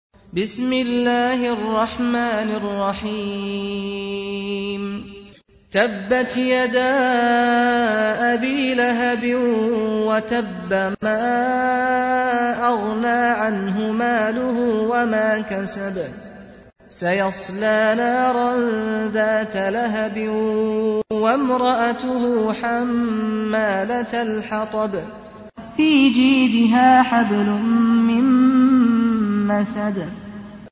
تحميل : 111. سورة المسد / القارئ سعد الغامدي / القرآن الكريم / موقع يا حسين